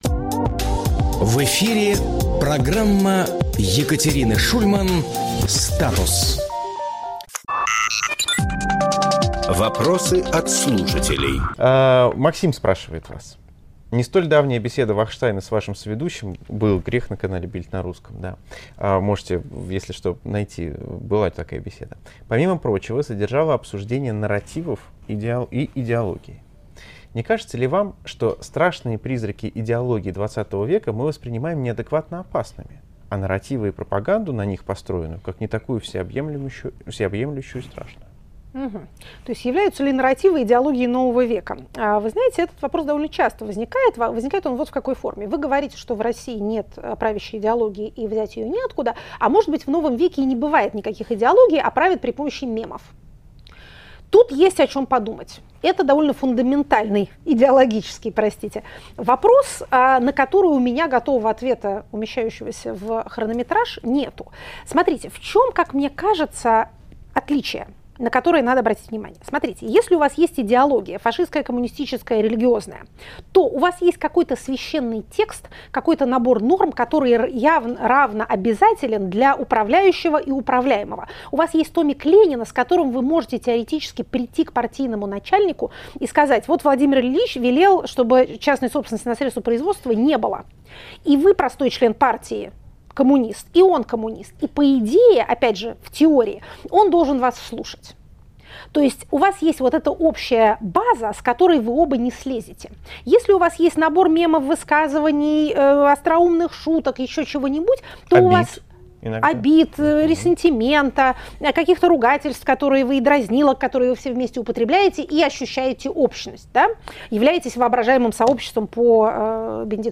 Екатерина Шульманполитолог
Фрагмент эфира от 15.04.25